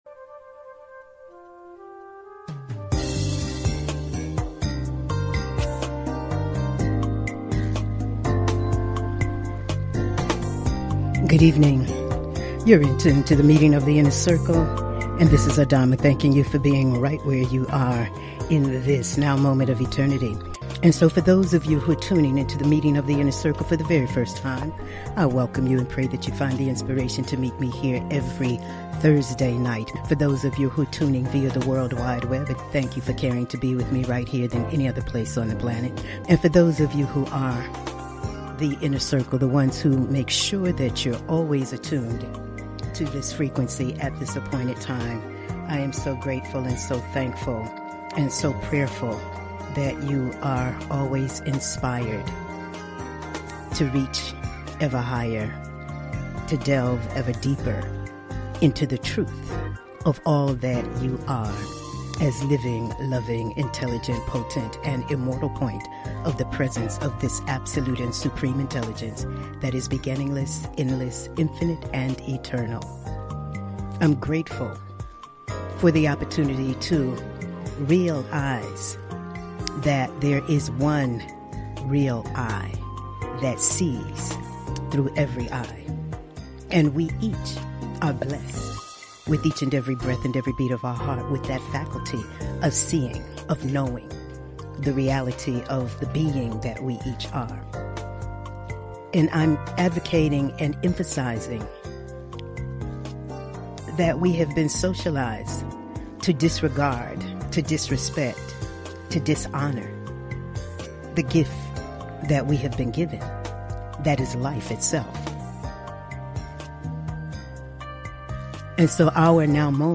Monologues
Talk Show